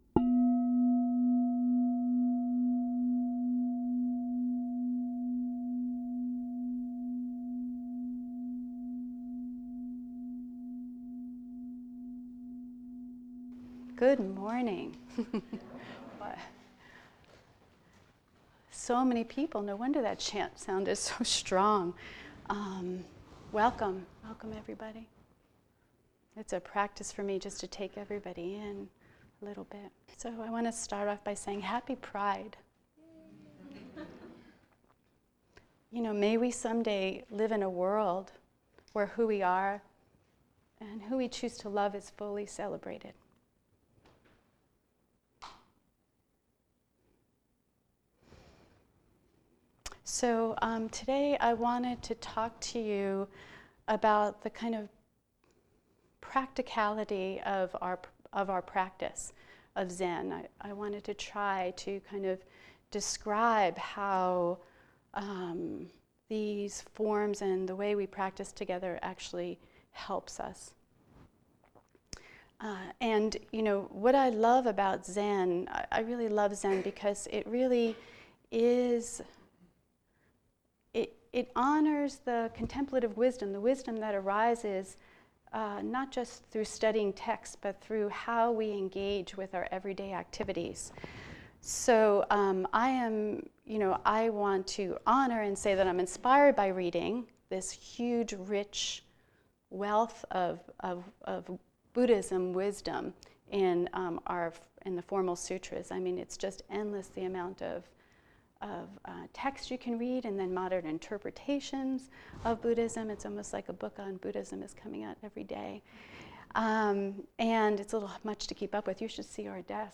New BZC Audio Dharma Talk - Brooklyn Zen Center